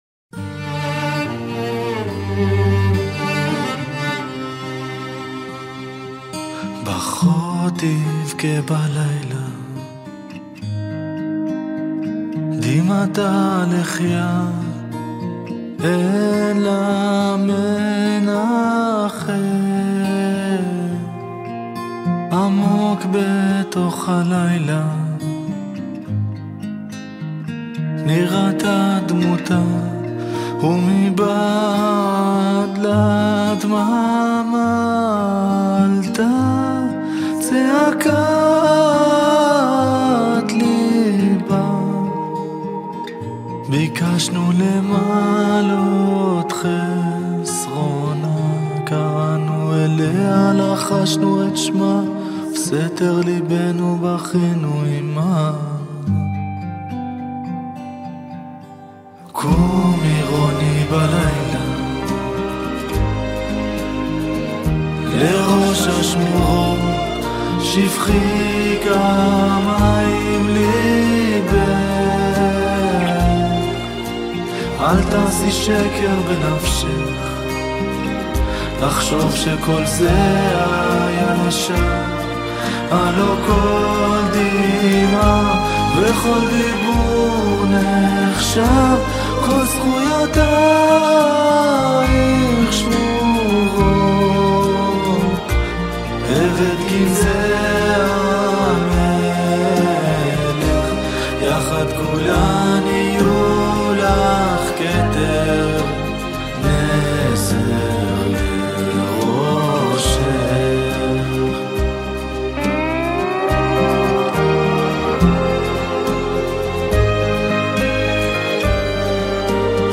קולות רקע